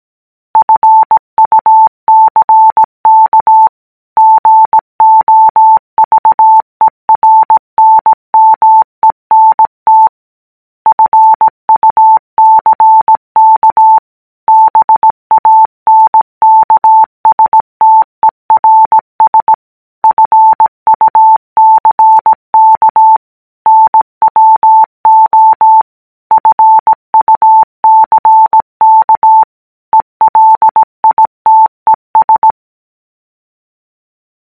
描述：摩尔斯电码。
Tag: 银行家 蜂鸣声 代码 政府 摩斯 NW 正弦